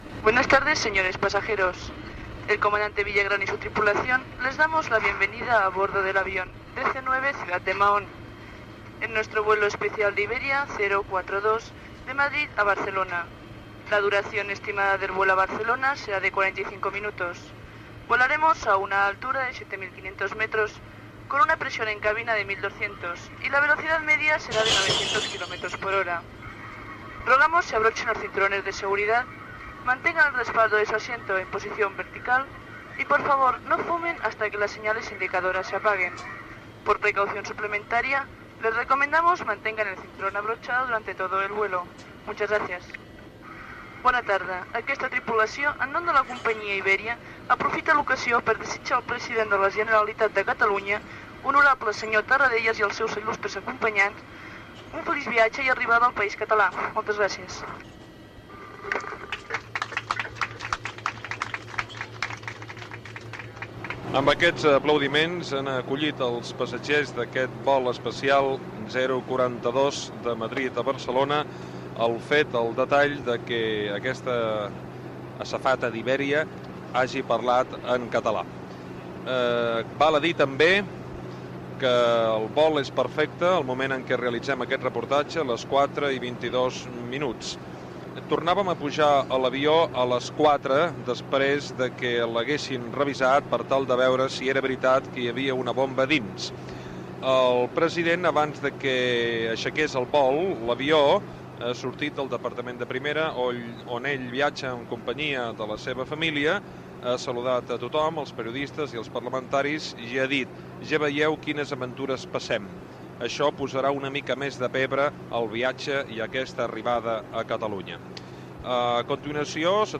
Reportatge fet a l'avió d'Iberia que porta el president Josep Tarradellas de Madrid a Barcelona. Declaracions de Josep Tarradellas
Informatiu